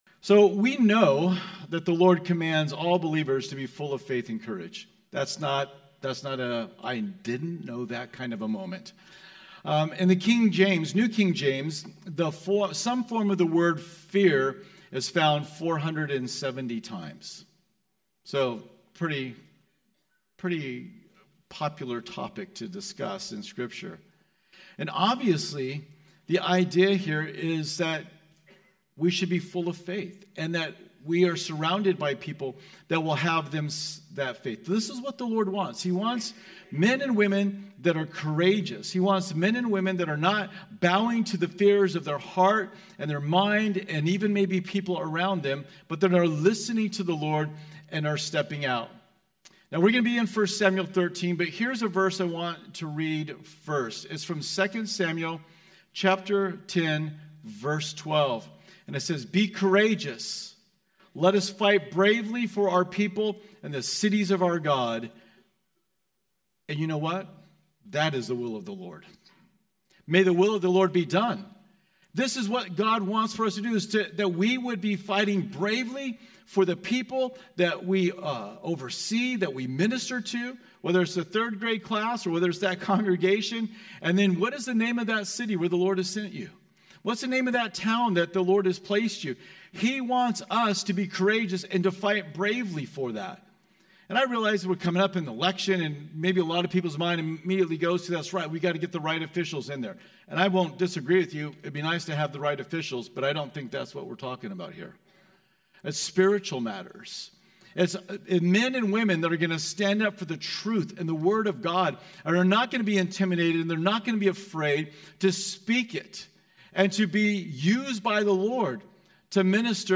Home » Sermons » “The Fearful Pastor”